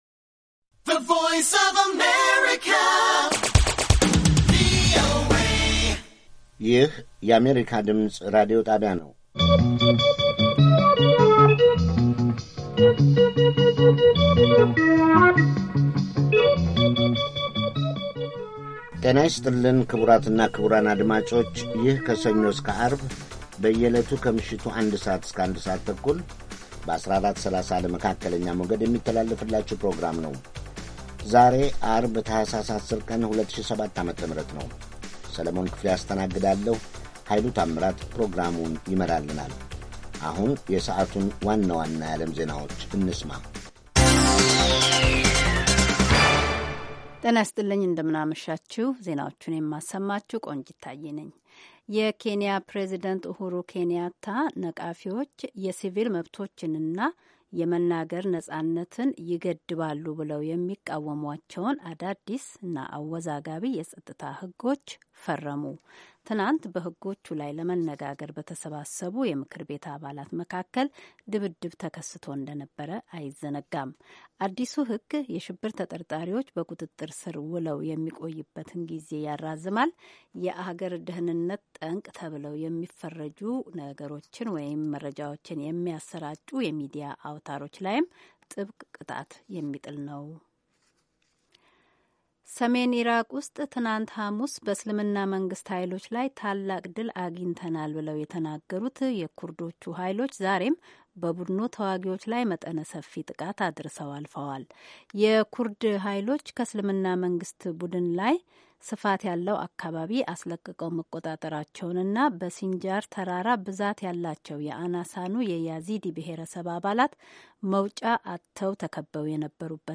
ከምሽቱ ኣንድ ሰዓት የአማርኛ ዜና